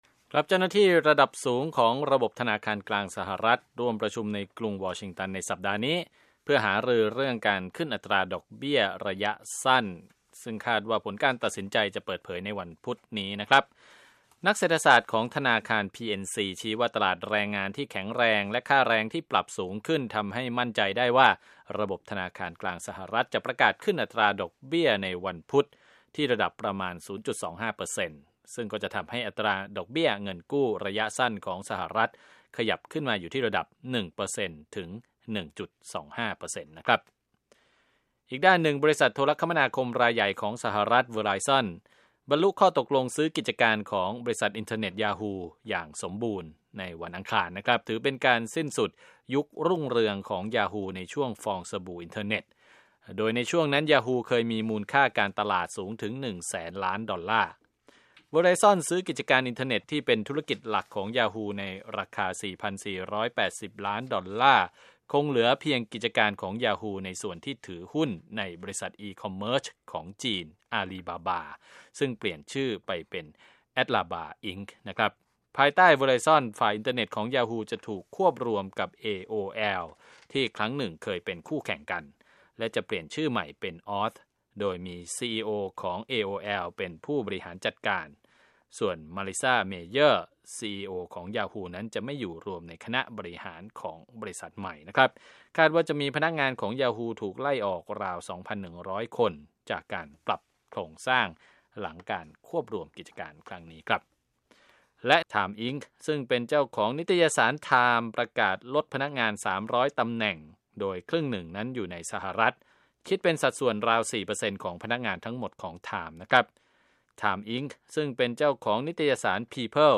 ข่าวธุรกิจประจำวันที่ 14 มิถุนายน 2560